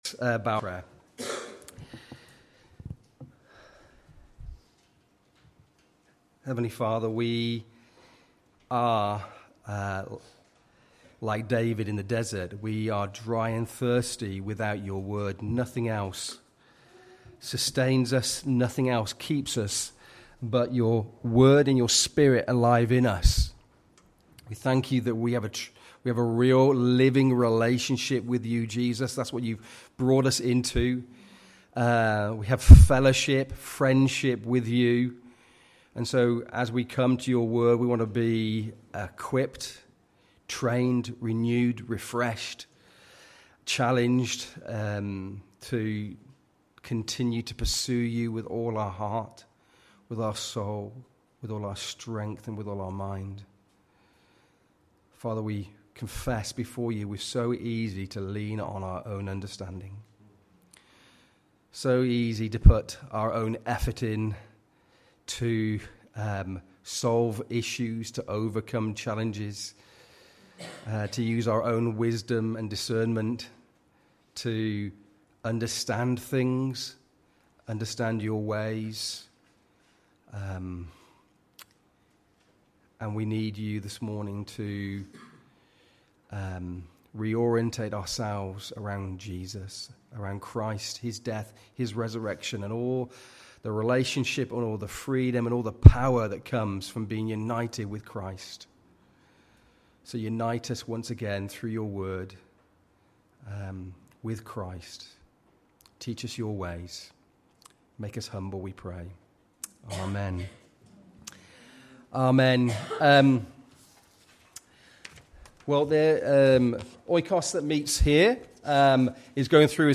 Sermons by OIKOS Church